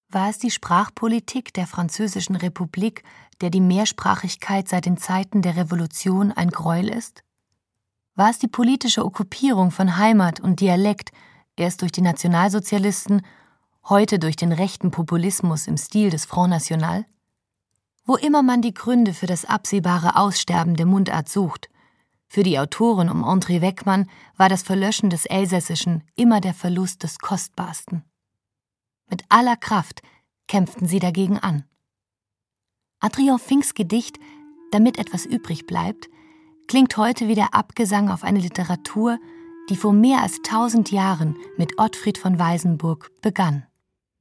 wandelbaren, dynamischen Stimme
Kein Dialekt
Sprechprobe: Sonstiges (Muttersprache):